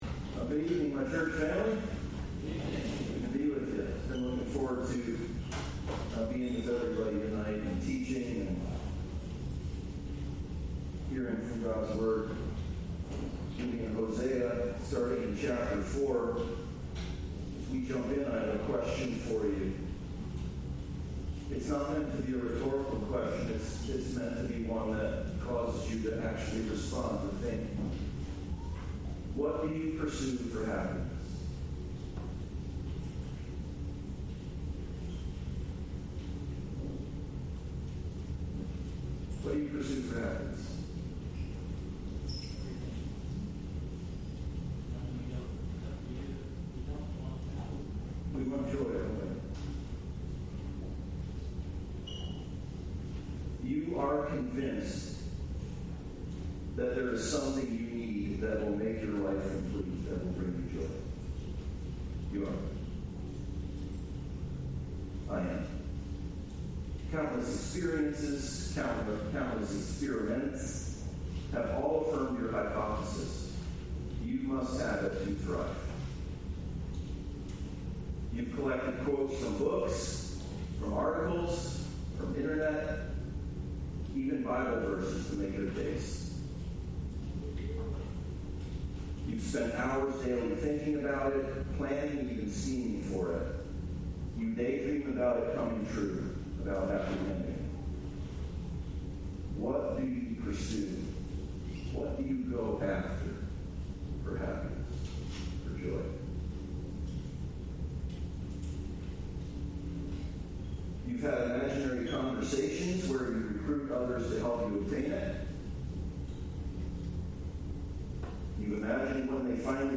Generous Grace Passage: Hosea 4:1-6:6 Service Type: Sunday Service « Generous Grace